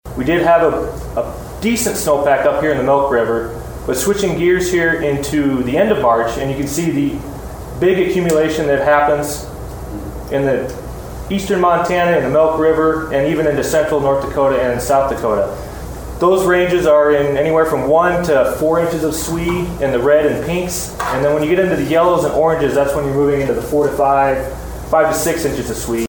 at a meeting in Bismarck